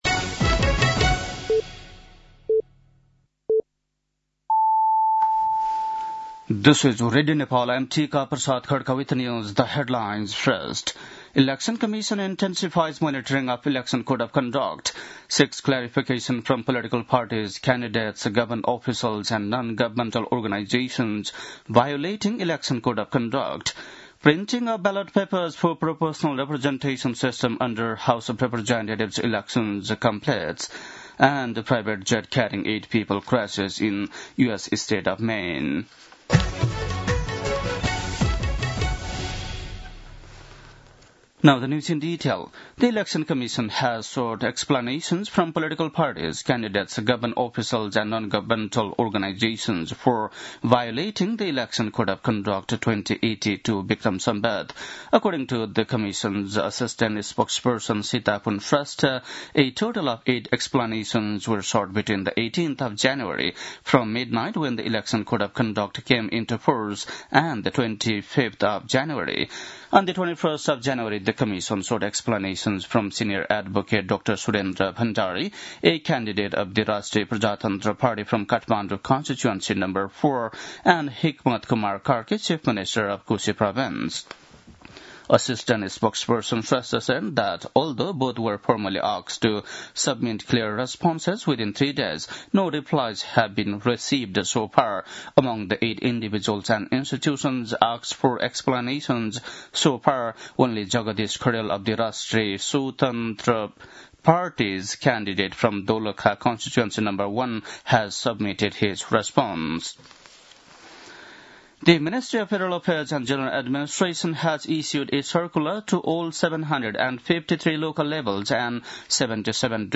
बेलुकी ८ बजेको अङ्ग्रेजी समाचार : १२ माघ , २०८२
8-pm-english-news-10-12.mp3